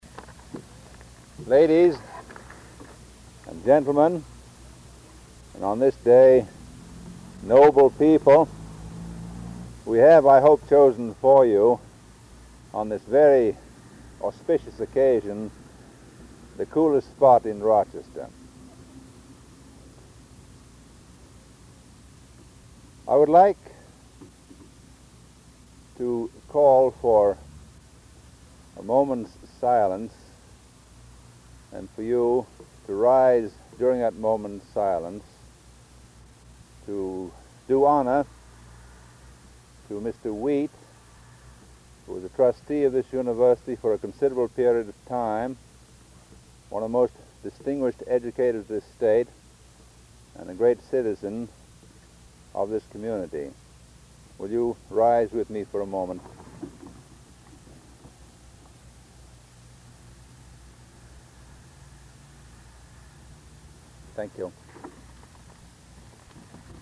1952 Groundbreaking for Susan B. Anthony Hall and Spurrier Gymnasium